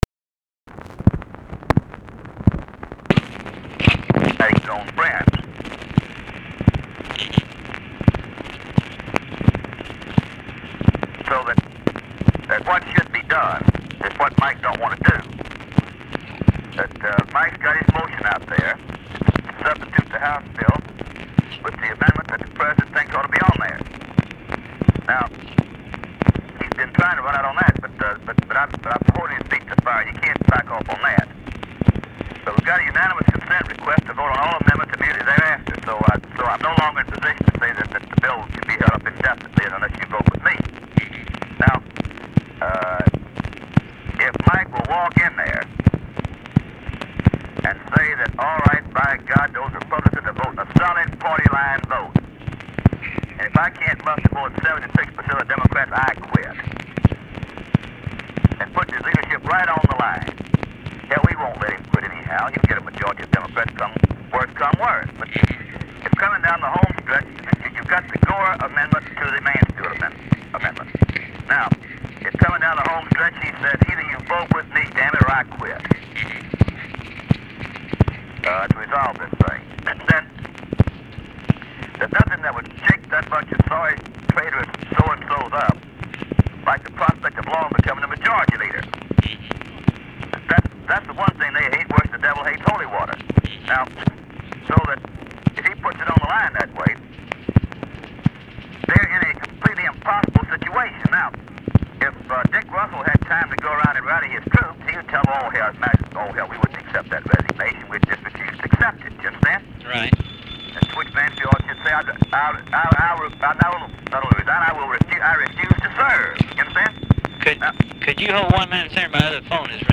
Conversation with RUSSELL LONG, May 5, 1967
Secret White House Tapes